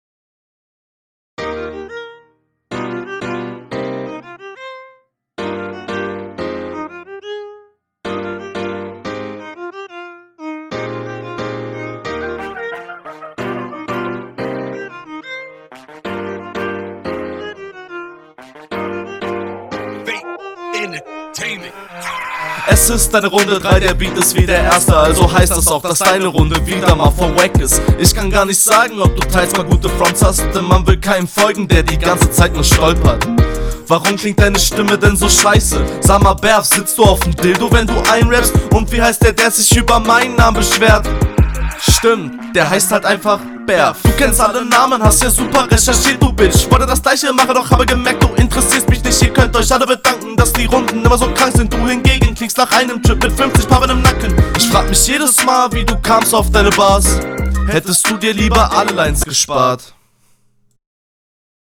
wieder ein cooler beat :D uff - du gehst 0 auf den beat ein.